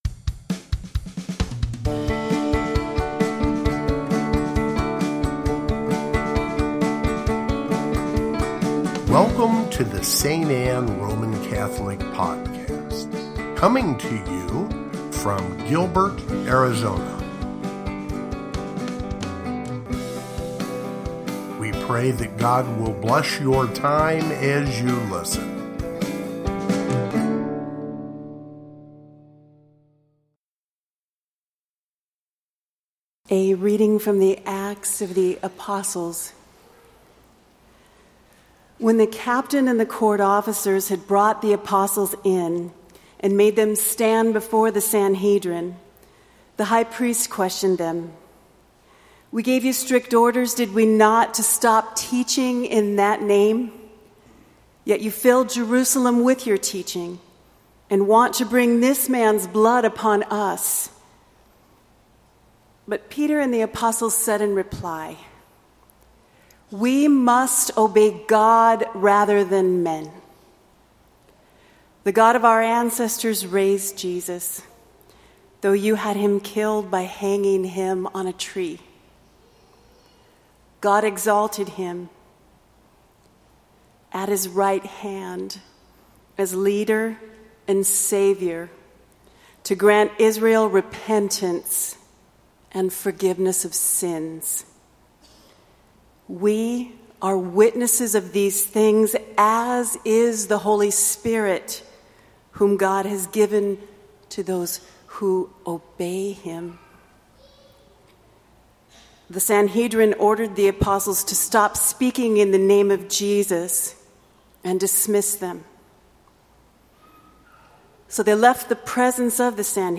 Third Sunday of Easter (Readings)
Gospel, Readings, Easter